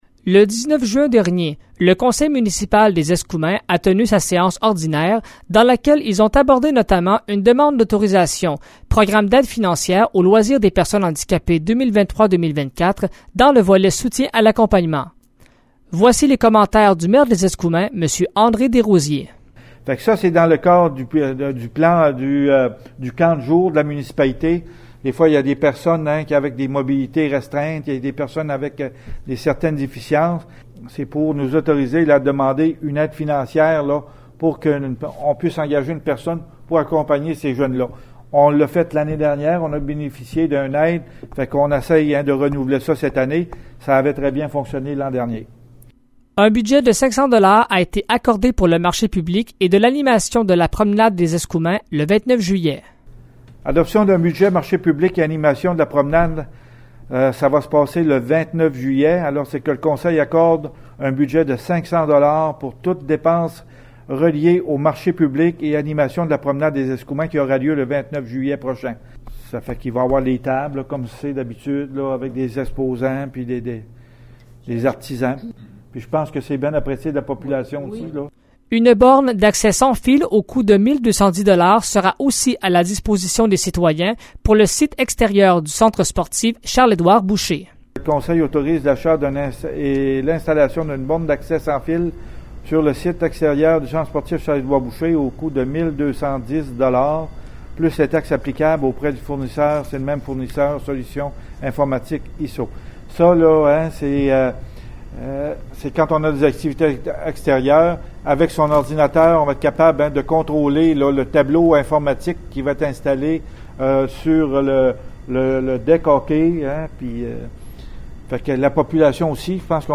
Voici un reportage